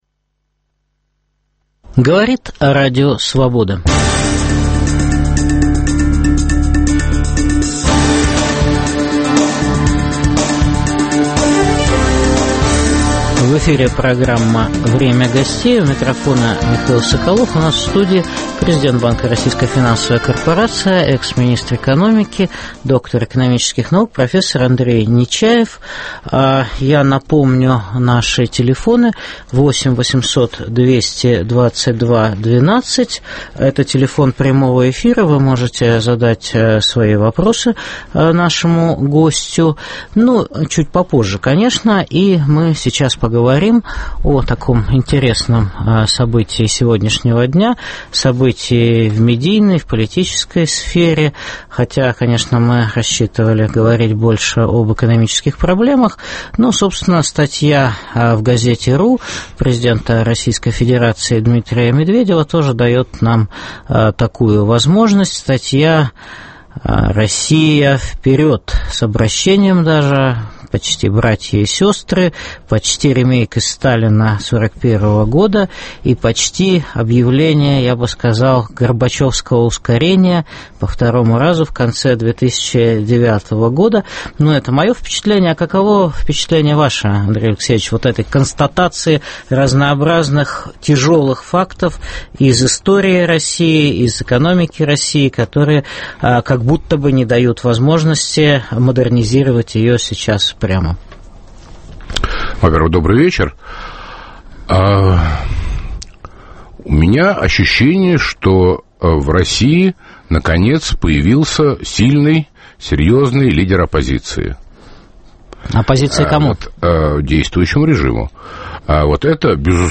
В программе выступит президент банка Российская финансовая корпорация", доктор экономических наук, профессор Андрей Нечаев.